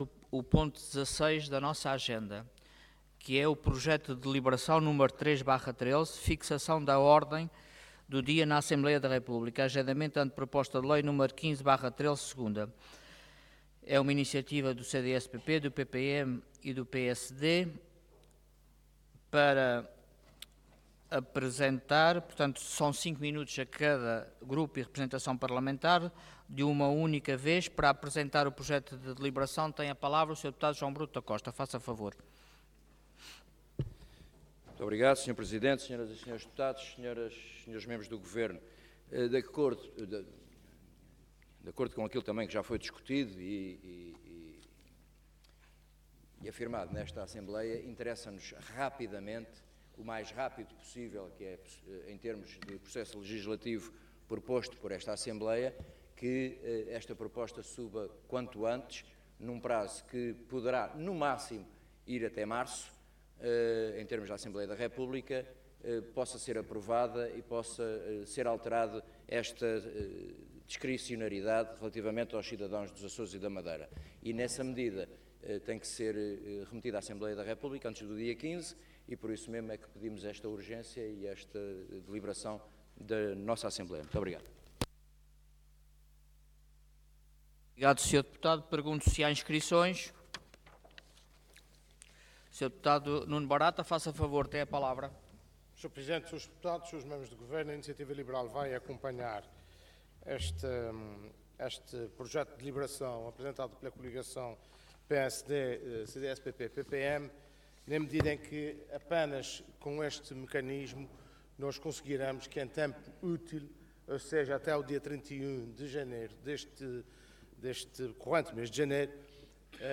Intervenção
Orador João Bruto da Costa Cargo Deputado